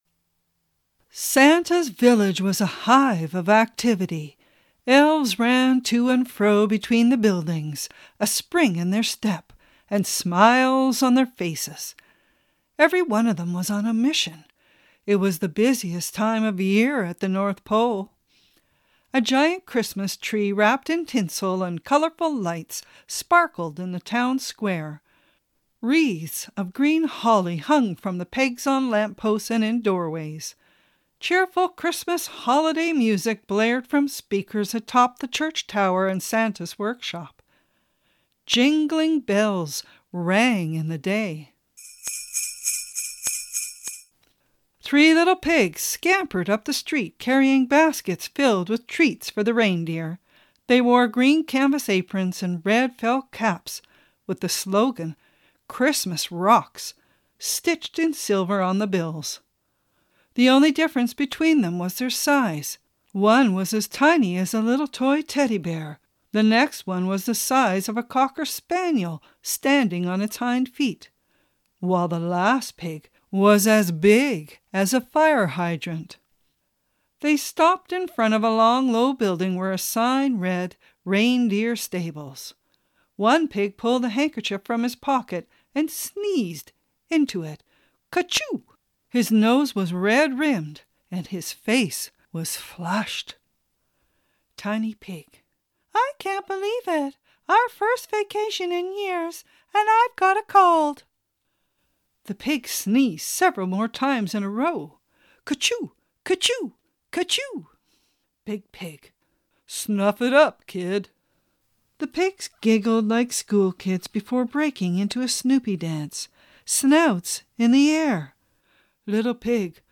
This is what started me reading these stories in the play format so that the younger and older children as well as those with learning disabilities could follow along.